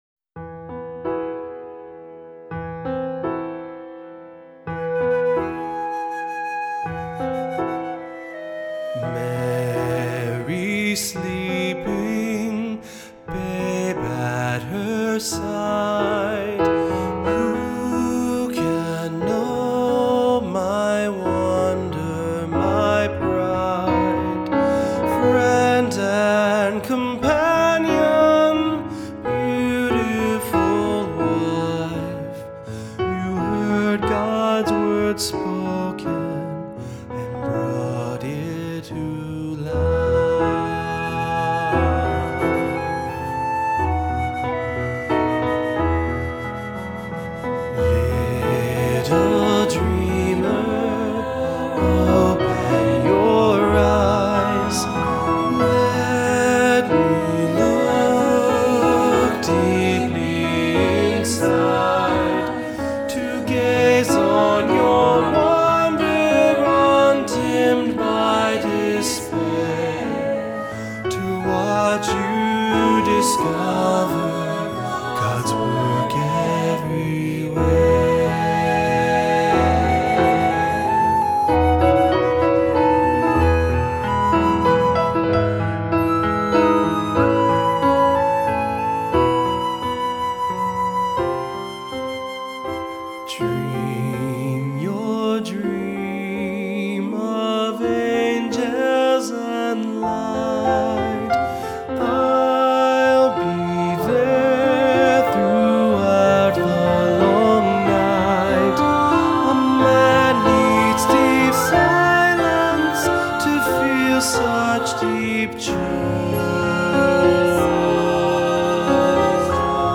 Voicing: Soloist or Soloists,3-part Choir